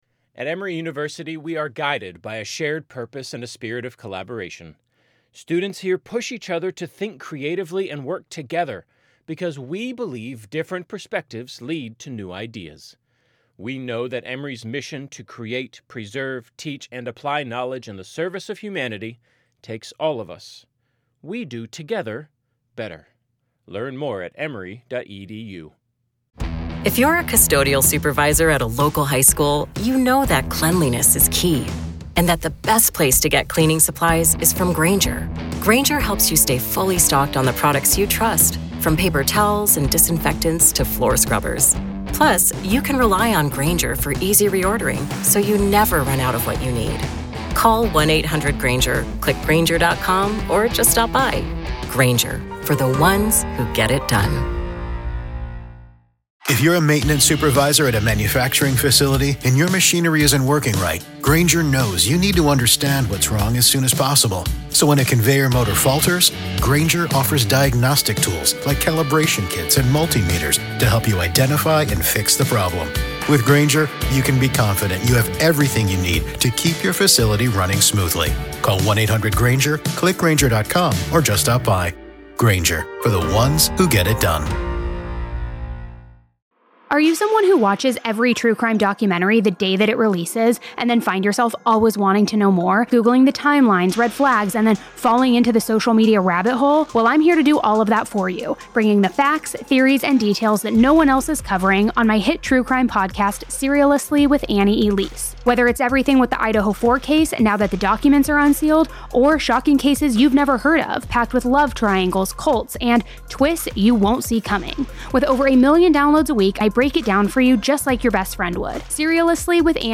In this gripping segment, we analyze one of the most critical pieces of evidence in the Alex Murdaugh case: his first full interrogation with police, recorded from the back of a cruiser at the chaotic crime scene.
We play the raw footage, allowing you to see and hear for yourself how Murdaugh describes the horrific discovery.